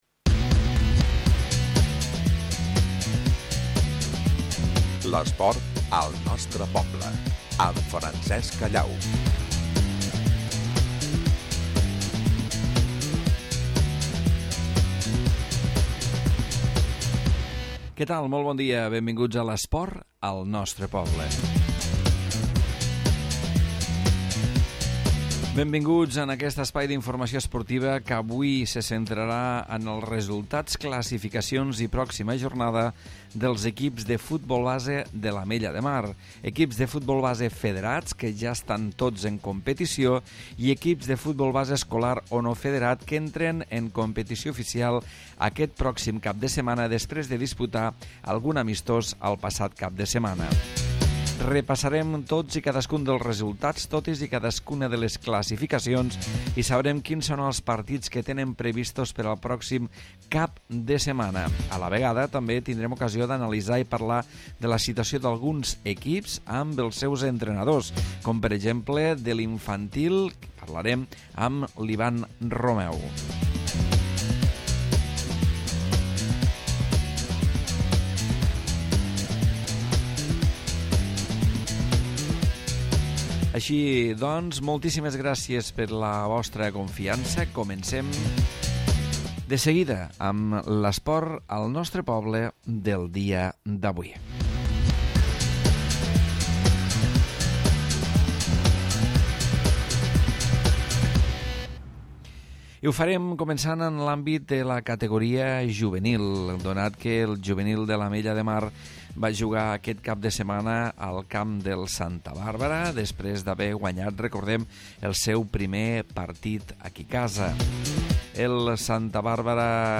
Espai esportiu d'àmbit local, avui centrat en repassar els resultats i les classificacions dels equips de futbol base de l'Ametlla de Mar. Entrevistem